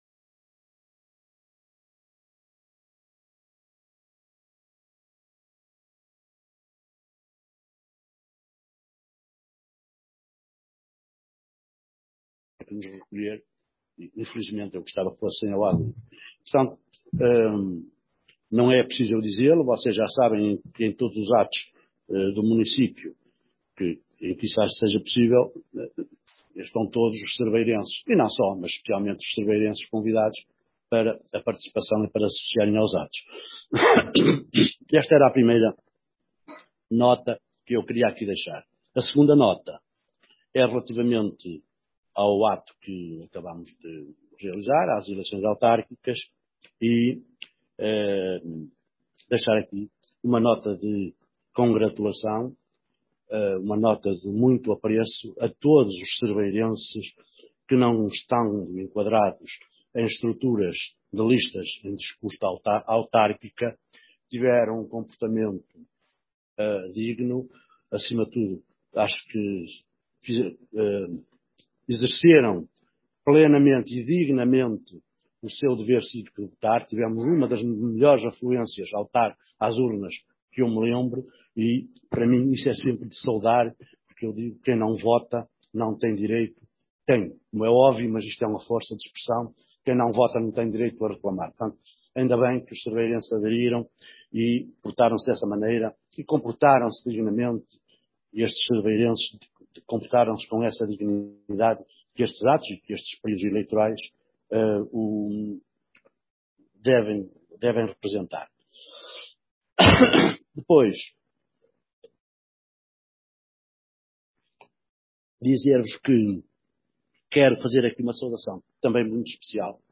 Documentação Pesquisar Pesquisar Documentação Histórico Atas 2021 30 setembro 2021 (c/ áudio) Sessão ordinária (problemas técnicos detetados provocaram alguns cortes no início da sessão) Ata 30 setembro 2021